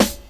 • 2000s Dry Acoustic Snare F# Key 09.wav
Royality free steel snare drum sample tuned to the F# note. Loudest frequency: 2499Hz